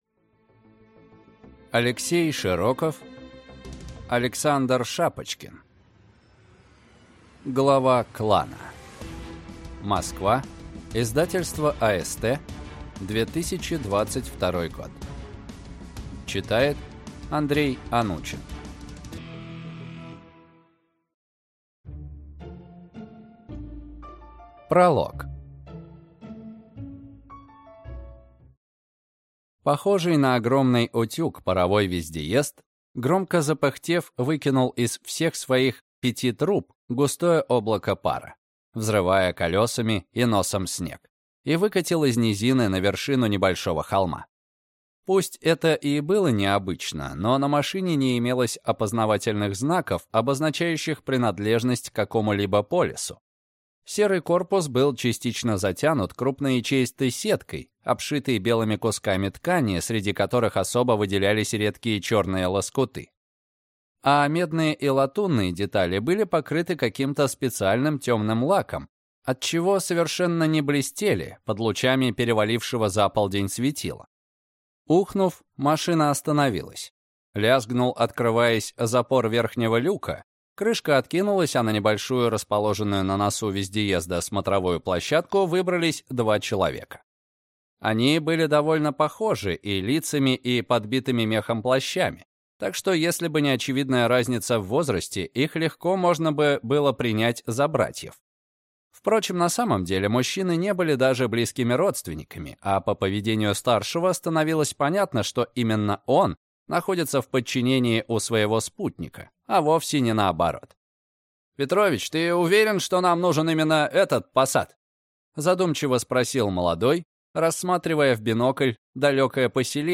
Аудиокнига Глава клана | Библиотека аудиокниг